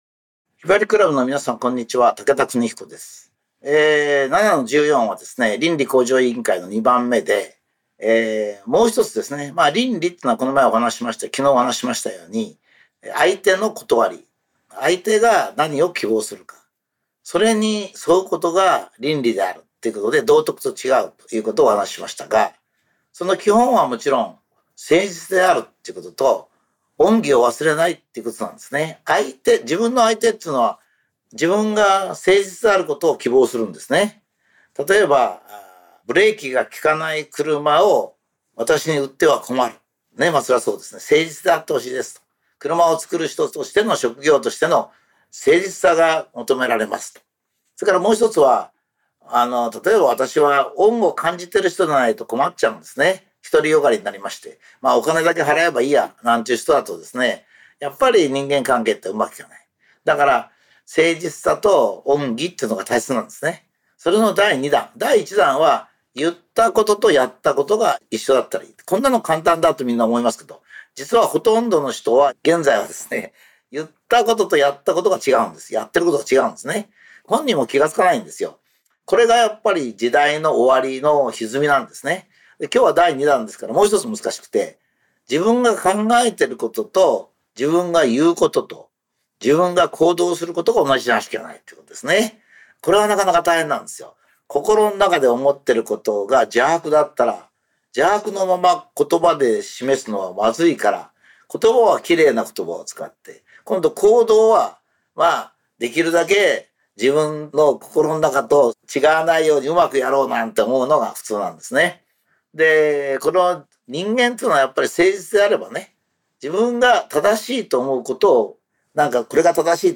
機材トラブルにより最後まで収録出来ていません。